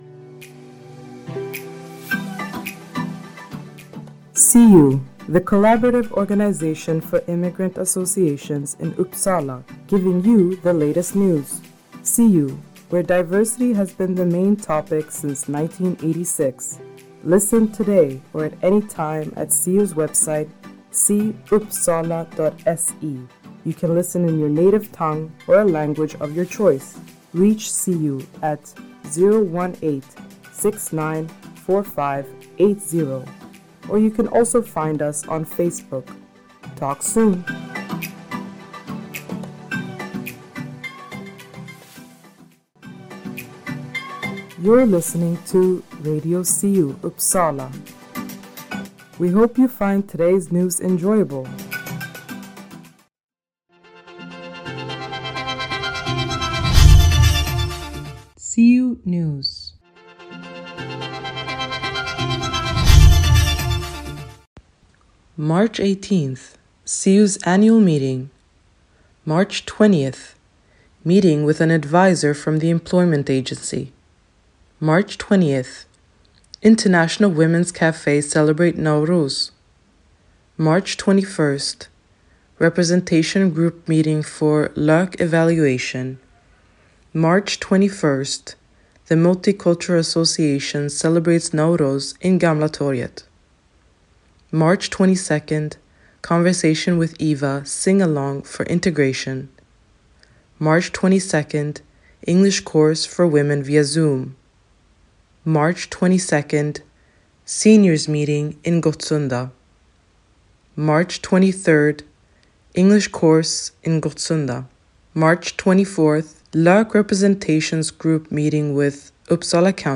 Siu-programmet på Engelska innehåller SIU:s nyheter, Nyheter Uppsala och Riksnyheter. Berika din fritid med information och musik.